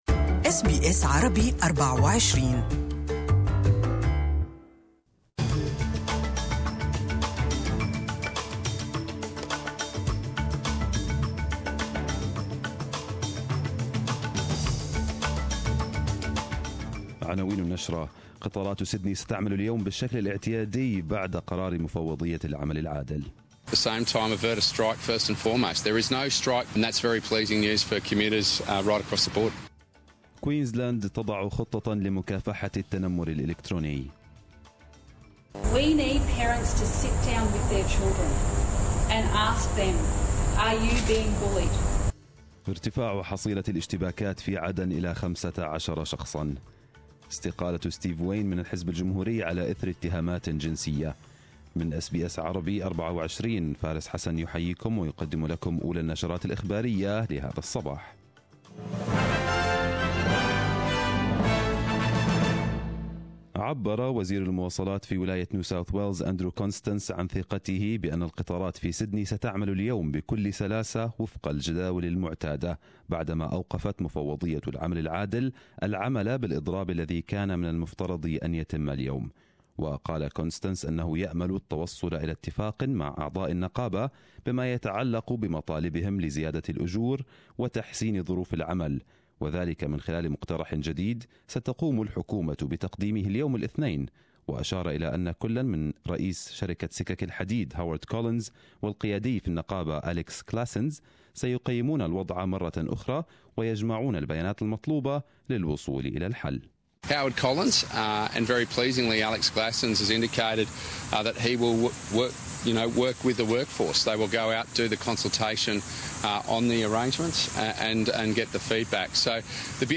Arabic News Bulletin 29/01/2018